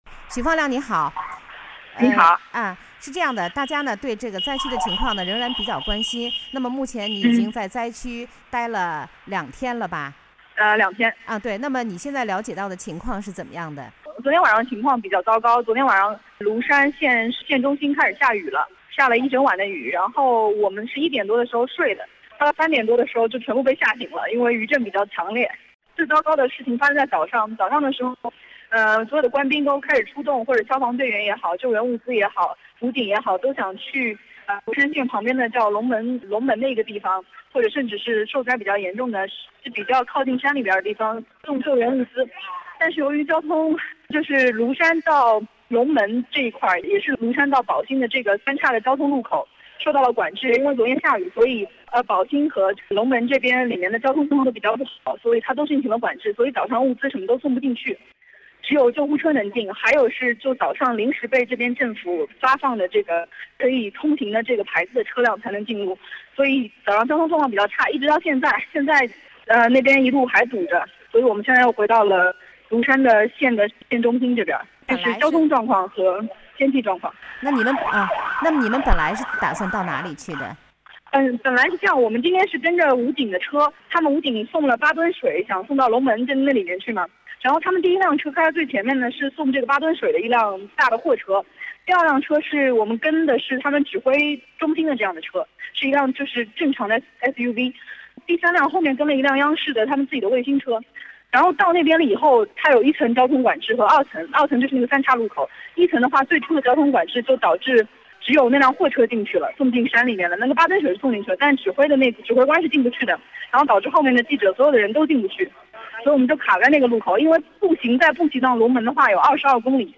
Here are some radio live hits I did with a Chinese radio station in Wuxi, Jiangsu Province, China. 3:57 April 21, 2013 5:20 April 22, 2013 3:50 April 23, 2013 0:31 April 24, 2013